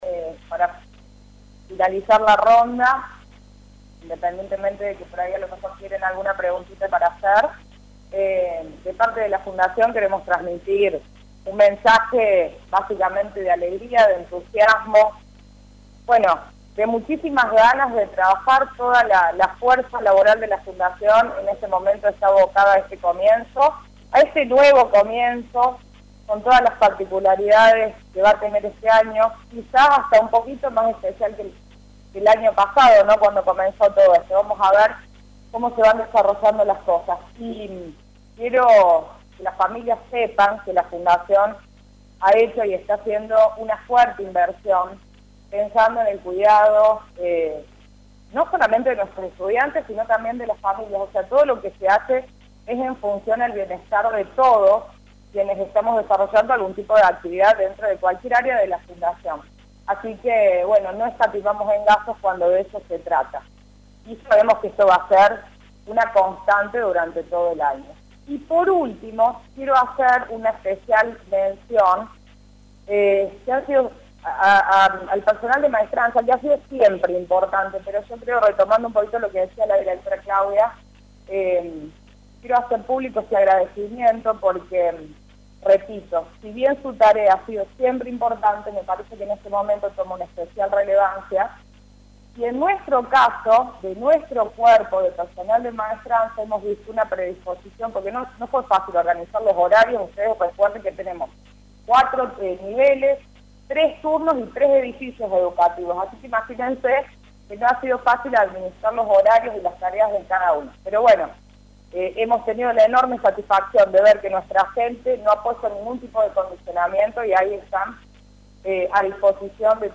En la mañana del miércoles las autoridades de los colegios del Complejo Educativo «Padre Jorge Isaac» de la Fundación San Jorge brindaron una conferencia de prensa con la información previa al inicio del ciclo lectivo (VIDEO COMPLETO  EN FACEBOOK).